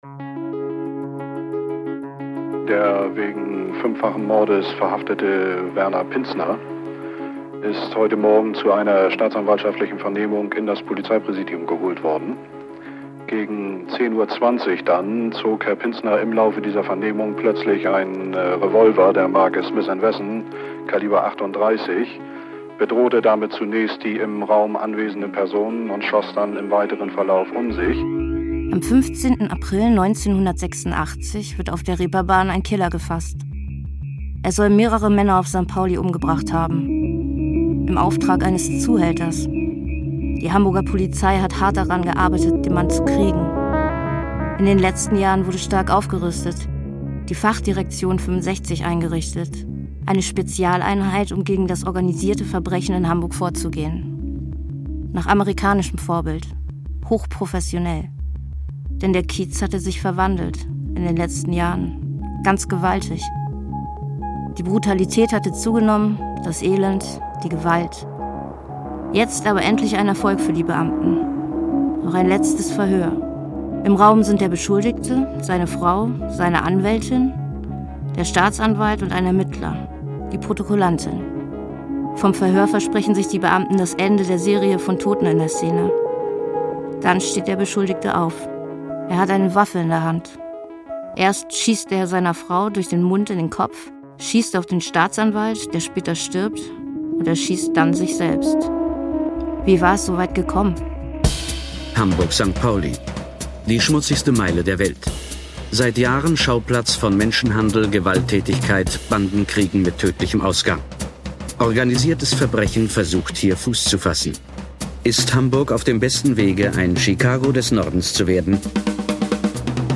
Erzählerin im Podcast: Julia Hummer.